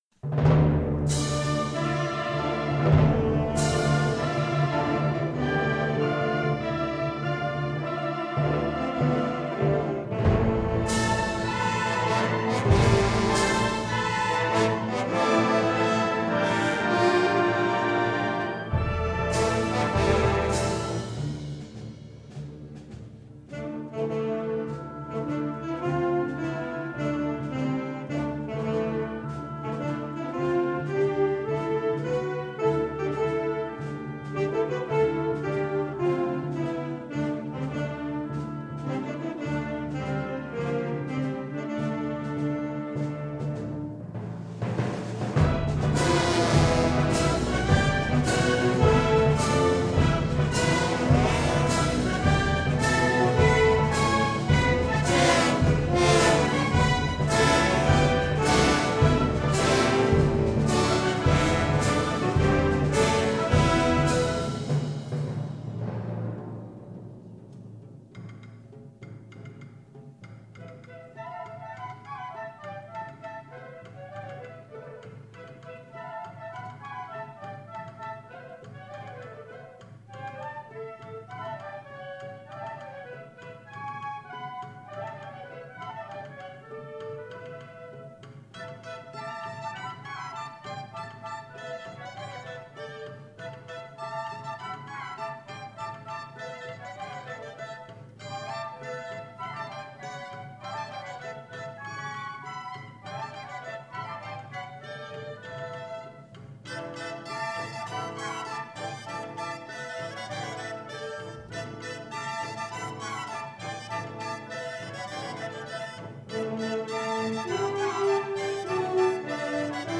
Christian march
Gender: Christian marches